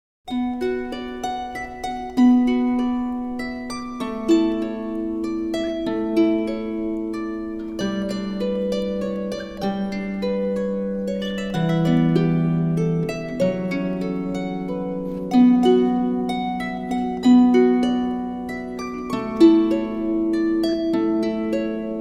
Музыка для детей с арфой